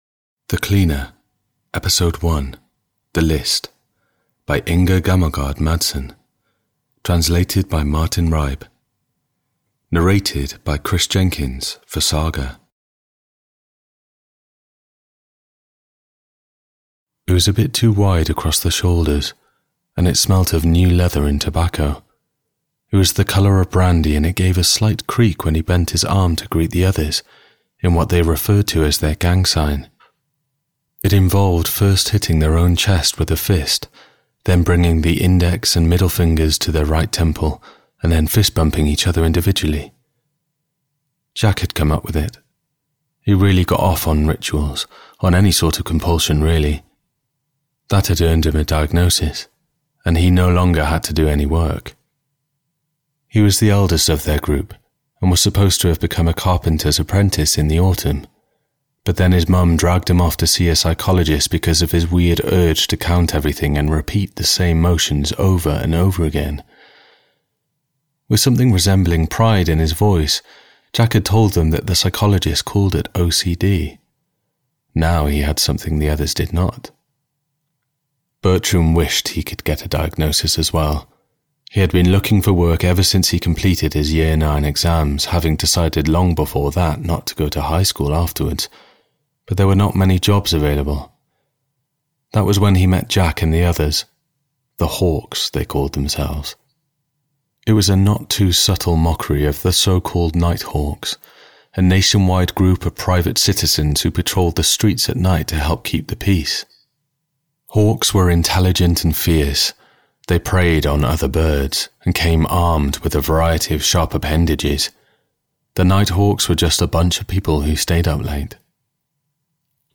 The Cleaner (EN) audiokniha
Ukázka z knihy